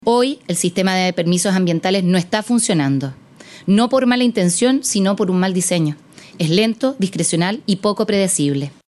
Cuenta Pública Sofofa
En paralelo, durante el miércoles se desarrolló la cuenta pública de la Sofofa.